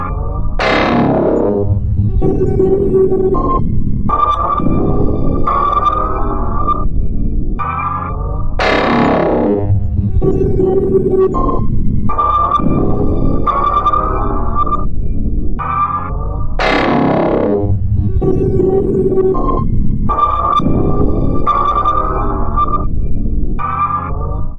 嗡嗡声
描述：实验性fx开发样本
标签： 实验 无人驾驶飞机 遗忘
声道立体声